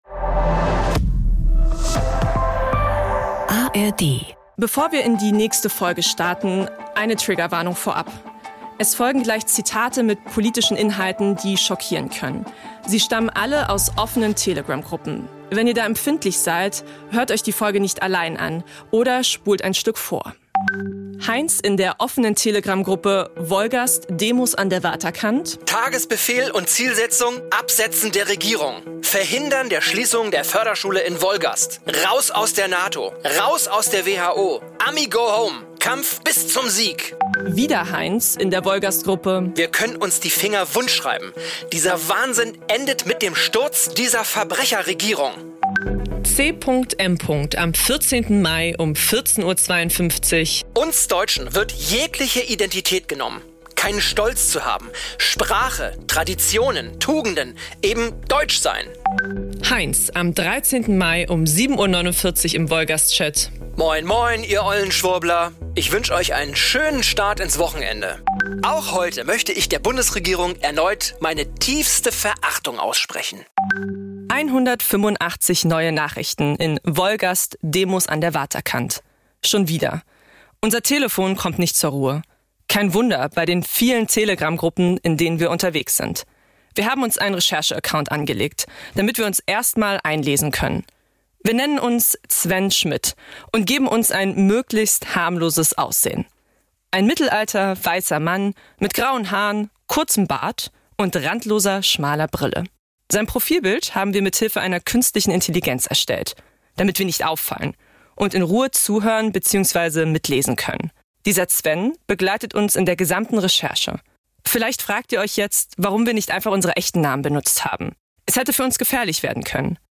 Wir fragen uns: Wie schaffen wir es, konstruktiv Medienkritik zu üben, ohne Fake News zu verbreiten oder auf sie hereinzufallen? Wir sprechen mit Demonstranten über ihren Medienkonsum und die sogenannten alternativen Medien. In Trent auf Rügen besuchen wir eine Dorfzeitung, die von Einwohnern für Einwohner gestaltet wird.